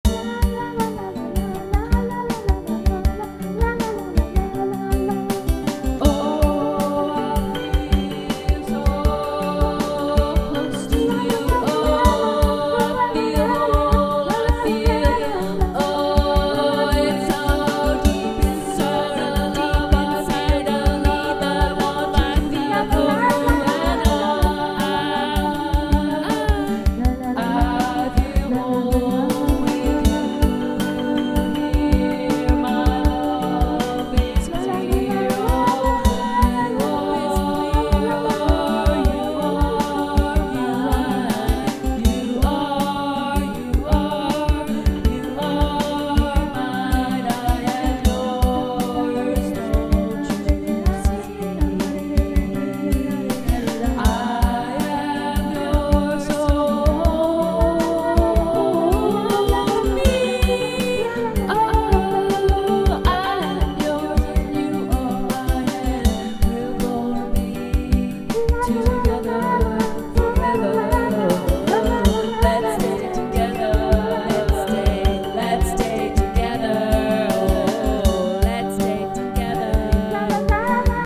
spoken word, songs, music, acoustic journal